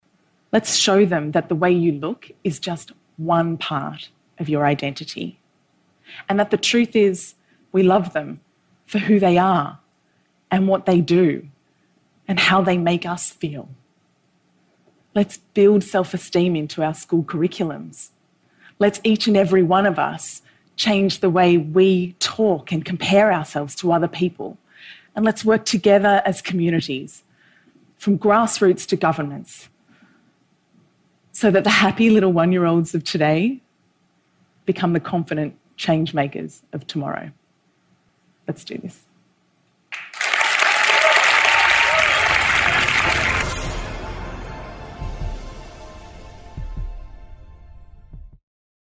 TED演讲:为什么认为自己丑会对你不利(7) 听力文件下载—在线英语听力室